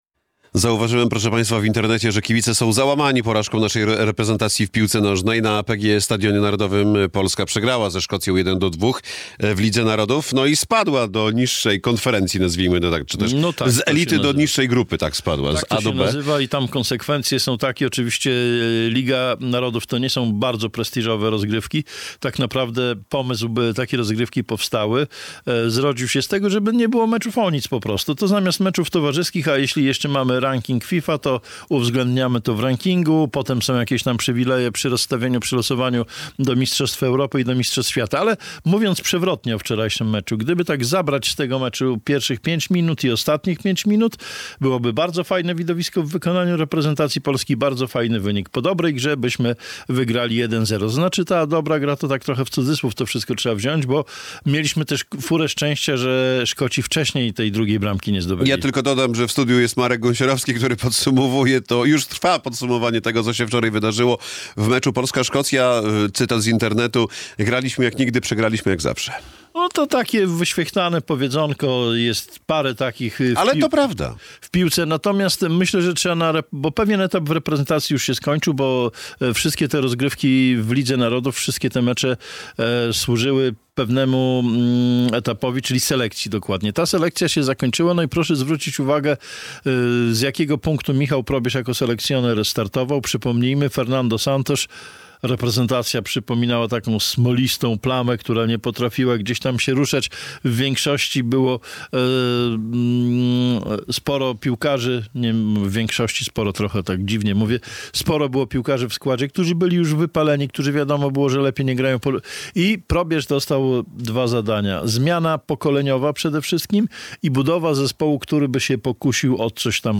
komentarz